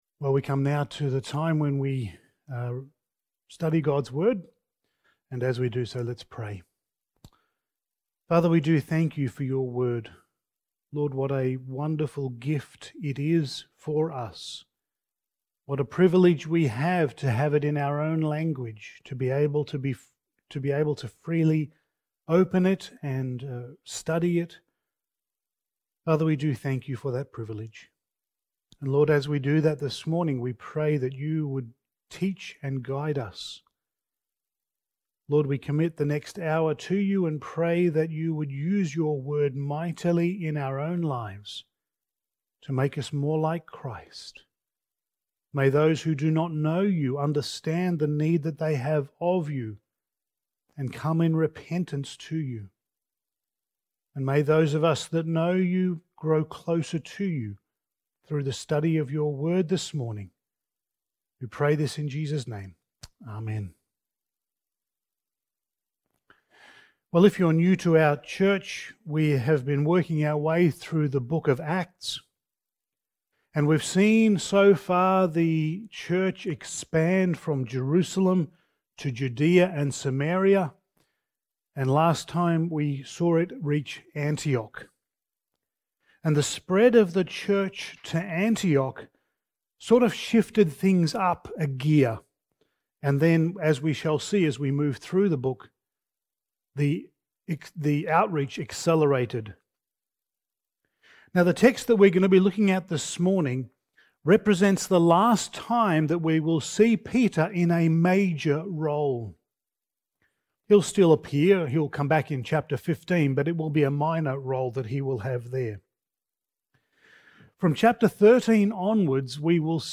Passage: Acts 12:1-24 Service Type: Sunday Morning